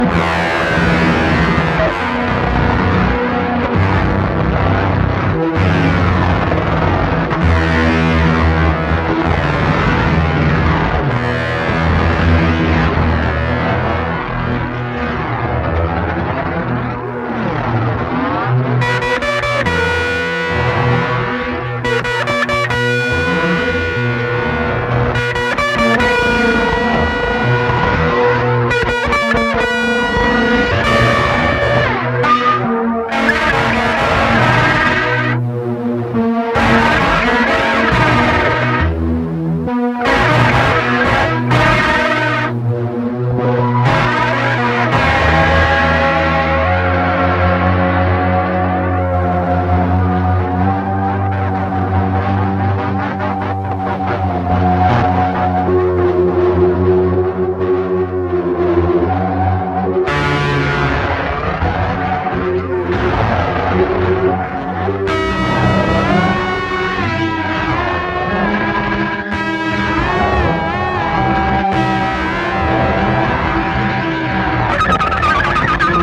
POST PUNK. / DUB